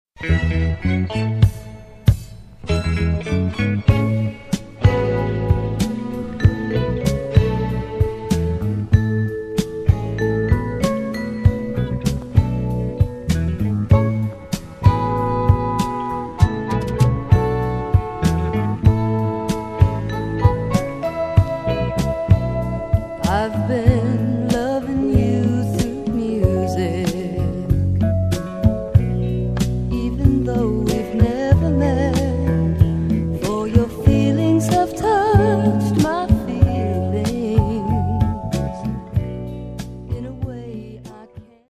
FREE SOUL/RARE GROOVE
より都会的なグルーヴとメロウなテイストを増したサード・アルバム